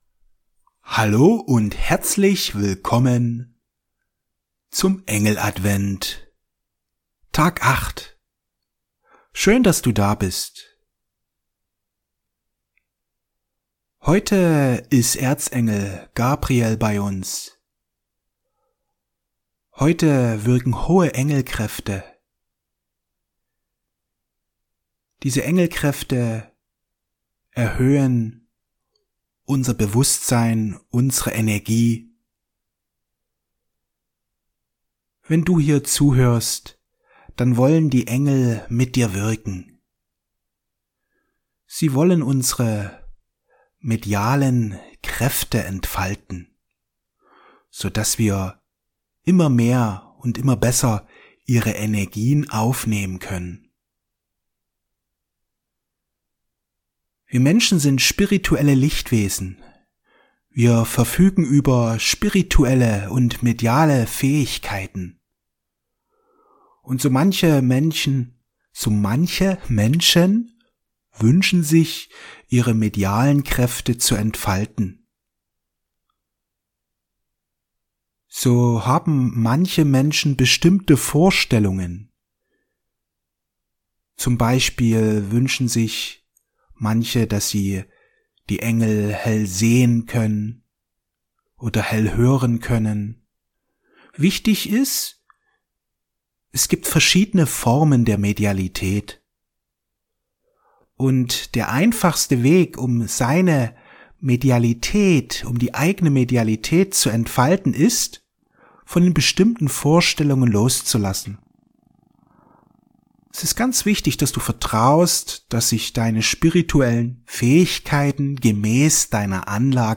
Die Entfaltung deiner spirituellen und medialen Fähigkeiten Meditation mit Erzengel Gabriel